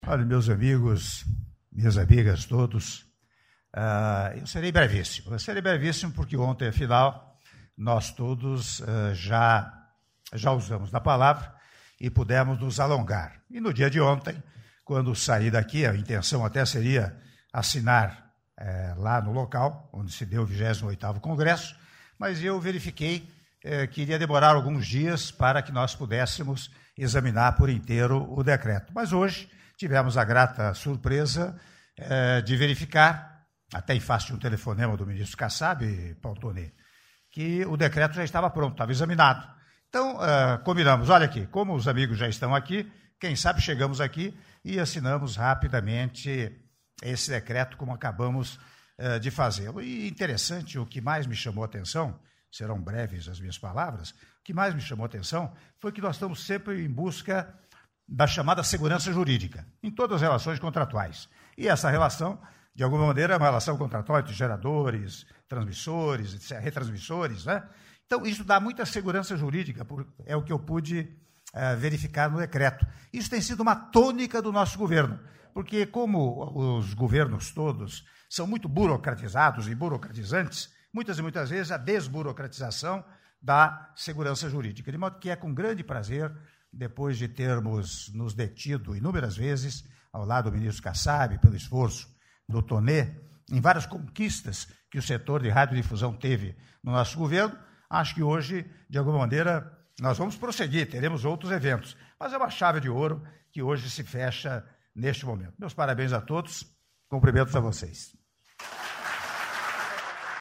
Áudio do discurso do Presidente da República, Michel Temer, durante Cerimônia de Assinatura do Decreto sobre Retransmissão e Repetição de Televisão e Radiodifusão - Palácio do Planalto (01min50s)